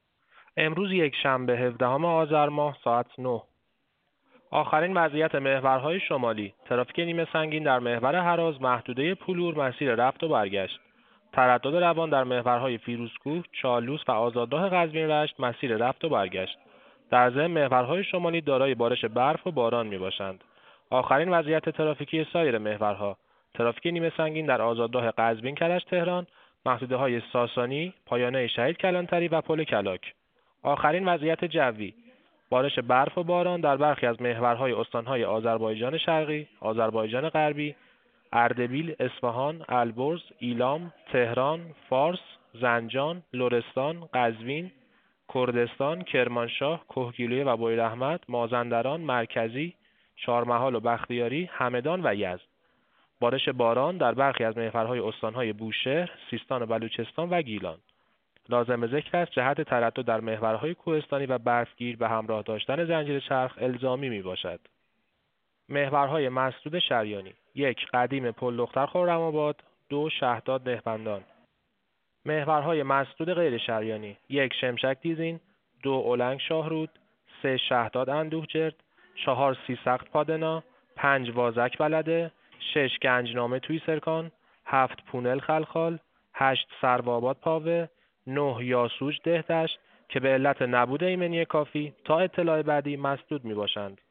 گزارش رادیو اینترنتی از آخرین وضعیت ترافیکی جاده‌ها تا ساعت ۹ هفدهم آذر ۱۳۹۸: